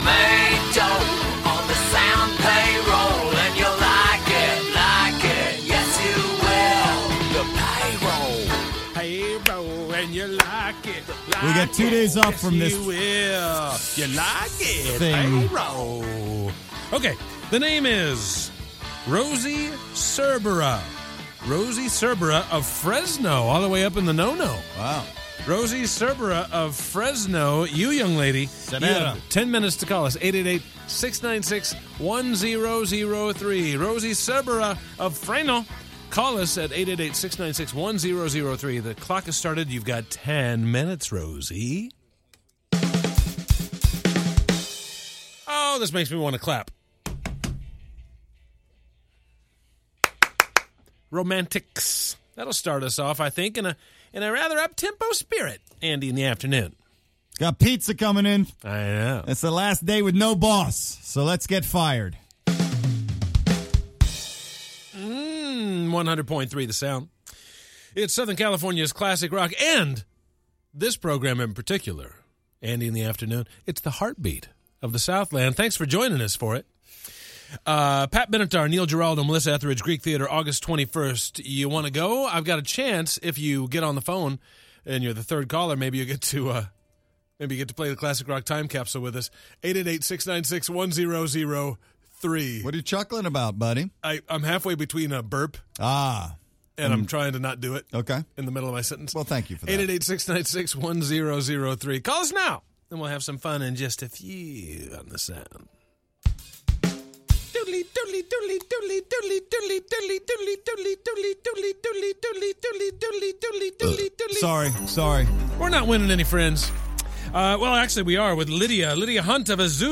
Pizza is delivered to the studio for a Friday Feast.